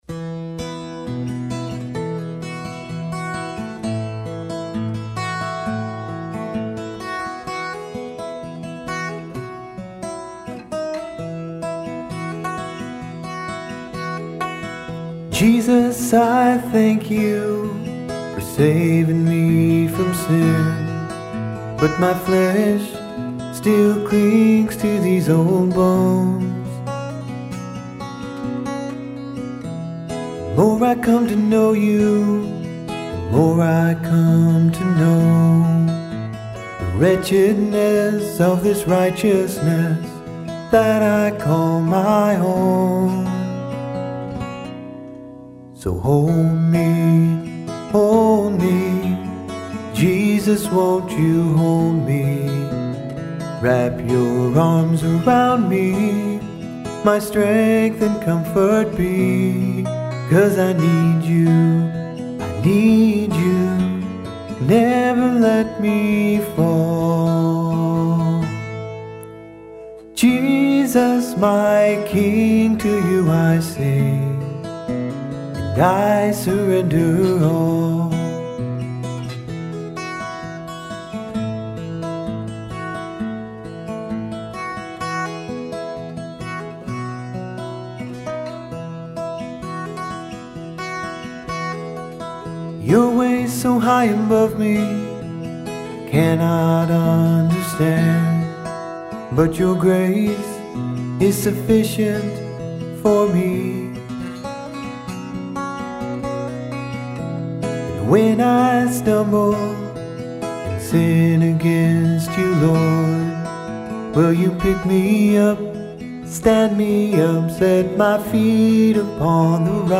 He wrote a gospel song called Hold Me. On this tune he recorded an acoustic guitar with a mic and an acoustic guitar with a pick up. And he sang the song.
I used a little waves doubler on the guitars to fill out the sound a little.
Ignore some of the technical issues like chair and strap noises and the such.